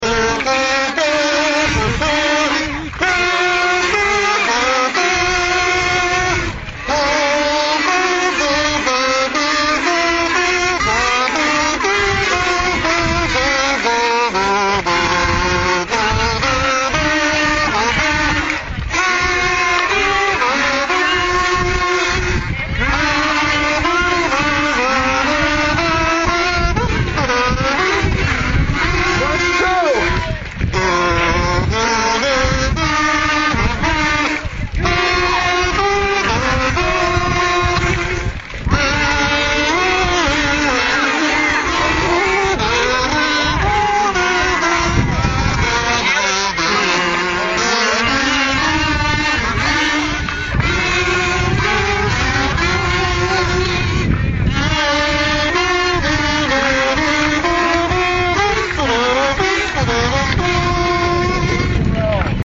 Between 20 and 30 kazoo virtuosos formed a band at the rear, while a large inflatable Uncle Sam let the parade.
leads the kazoo band
kazooanchors.mp3